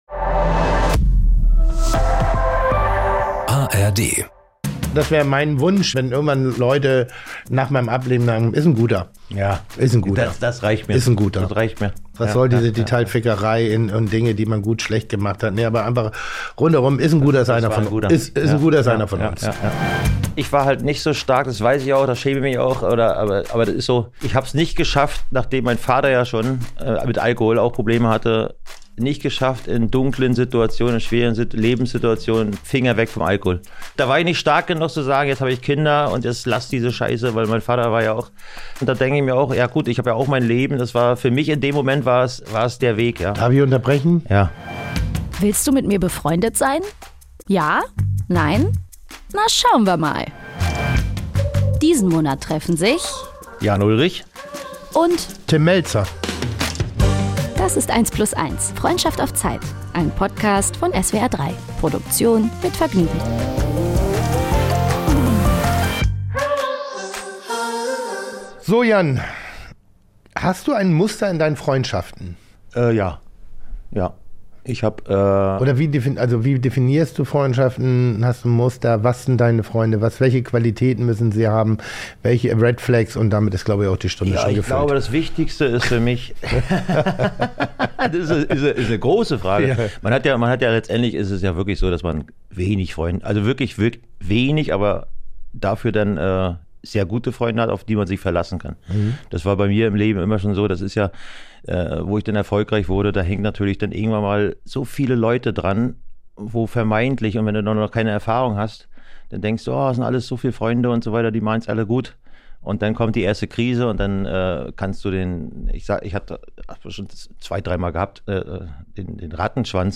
Eine Folge, die unter die Haut geht: Tim Mälzer spricht über seine eigene Beerdigung, Jan Ullrich darüber, wie Kokain und Alkohol ihm die Menschlichkeit genommen haben. Die zwei sind sich einig, dass man an gebrochenem Herz sterben kann, und dass wahre Freundschaft viel aushält.